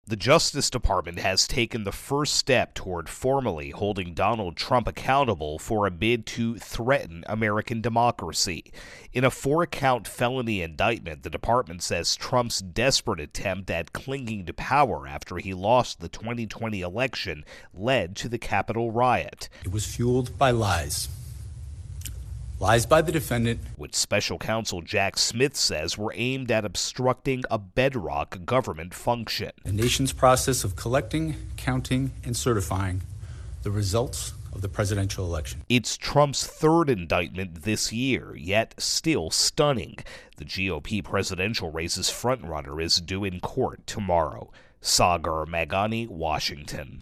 reports on Trump-Capitol Riot-intro + wrap 022